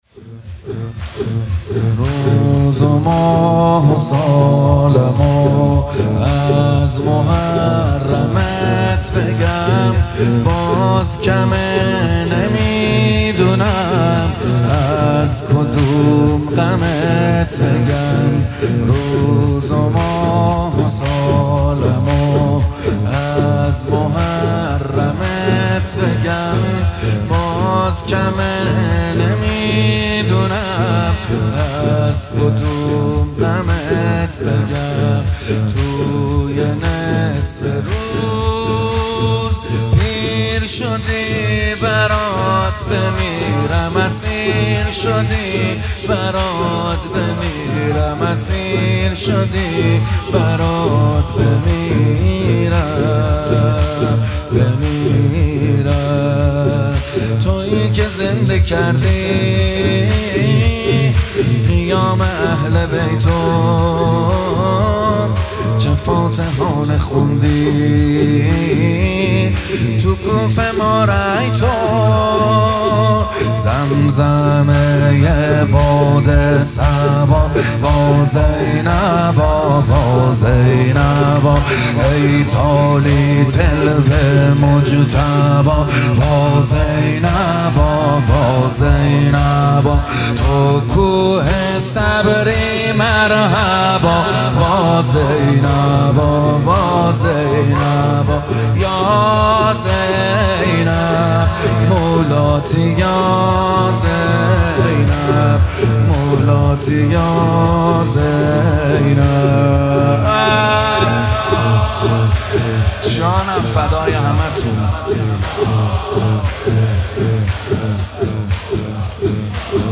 مراسم عزاداری دهه اول محرم سال ۱۴۰۱